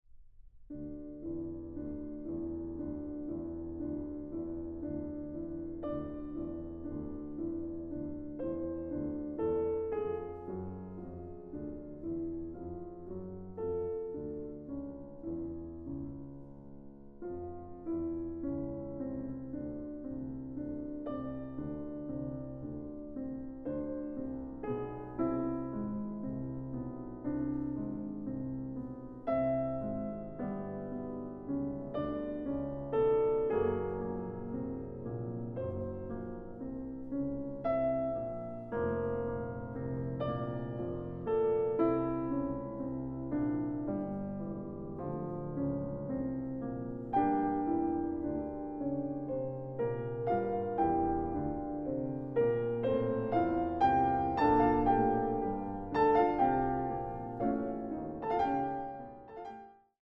Klavier
Aufnahme: Mendelssohnsaal, Gewandhaus Leipzig